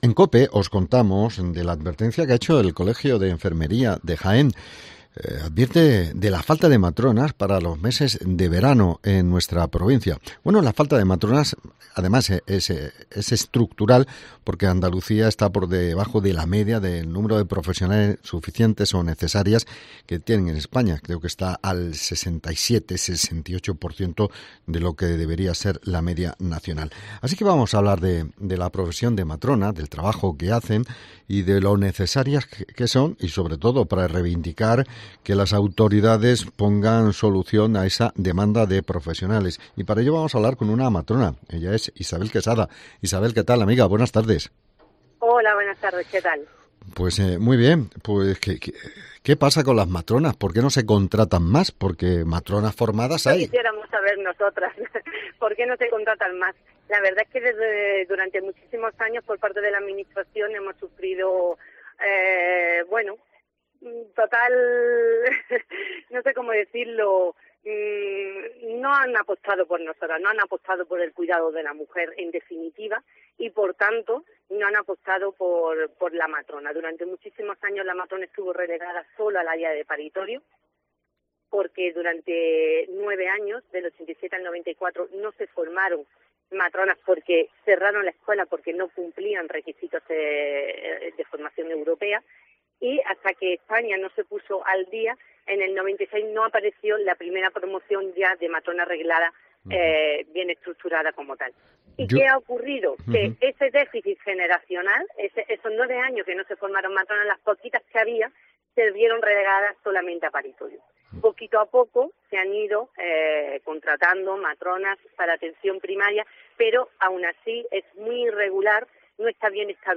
Hablamos con la matronas